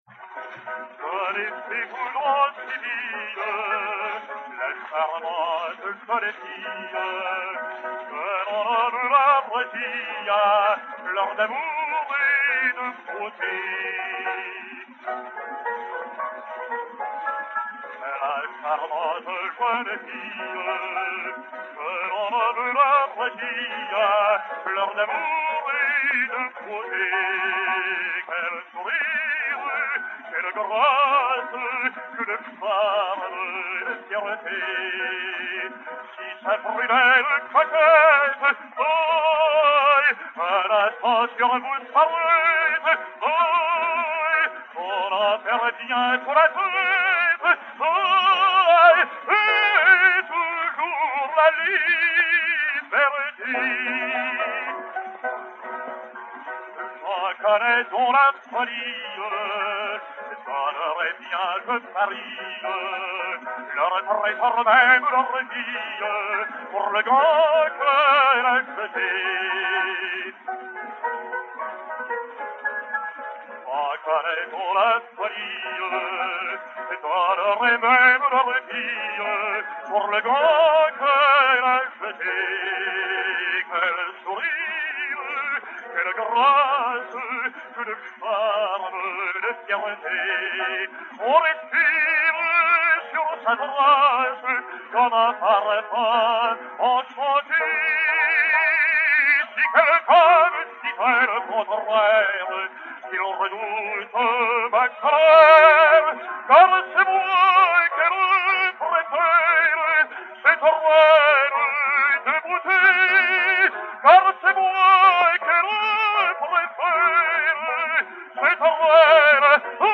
Francesco Tamagno (Jean) et Piano
Disque Pour Gramophone 52684, enr. à Milan en 1903